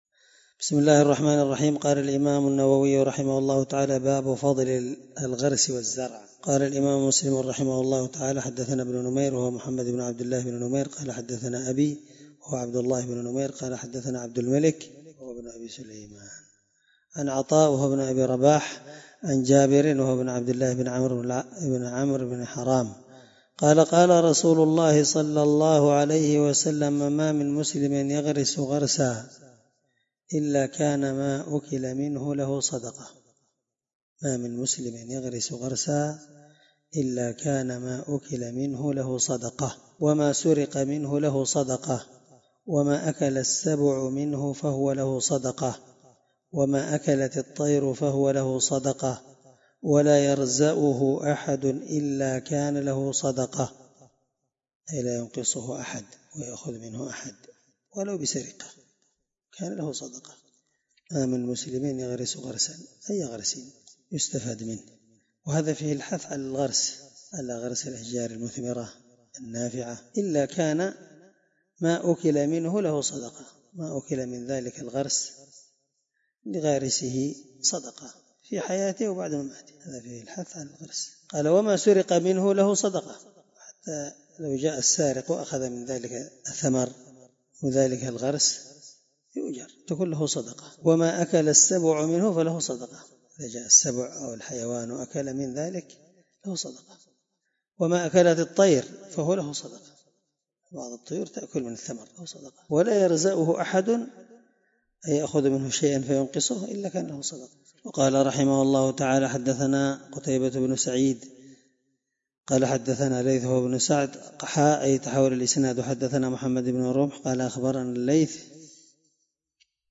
الدرس2من شرح كتاب المساقاة حديث رقم(1552-1553) من صحيح مسلم